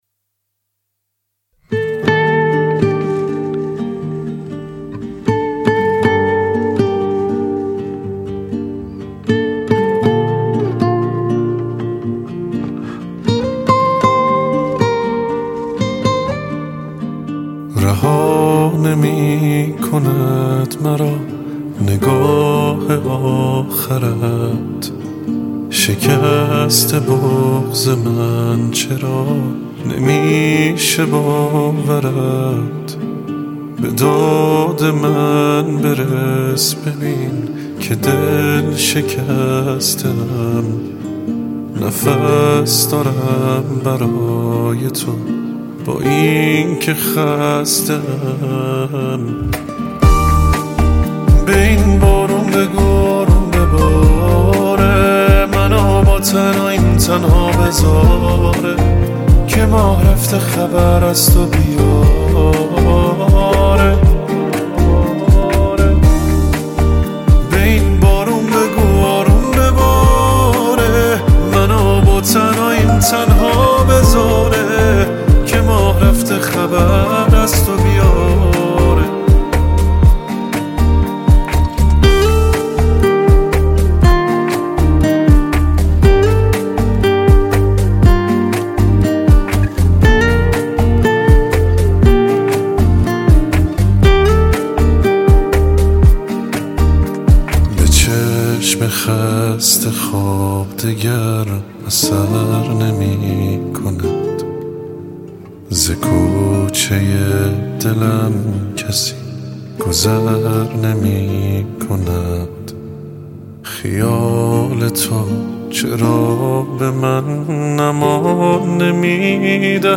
Slow Version